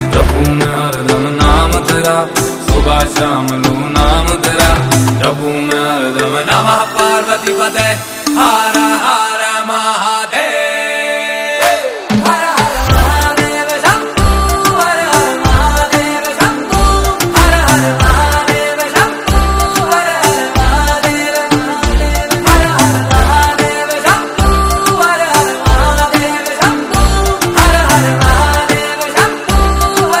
Powerful and devotional